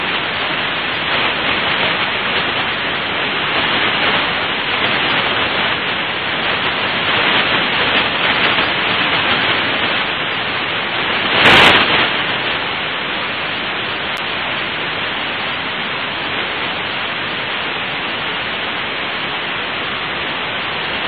Это электромагнитные колебания, переведенные в слышимый диапазон.
Штормовые звуки Юпитера